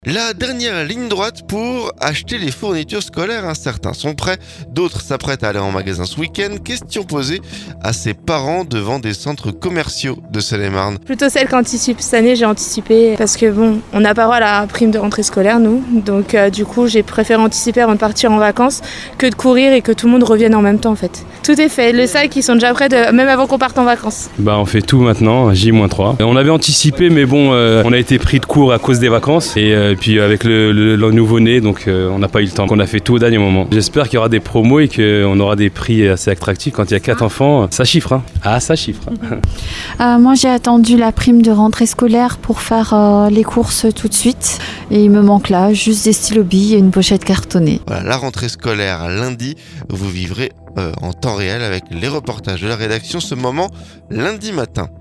Certains sont prêts, d'autres s'apprêtent à aller en magasin ce week-end. Question posée à ces parents devant des centres commerciaux de Seine-et-Marne.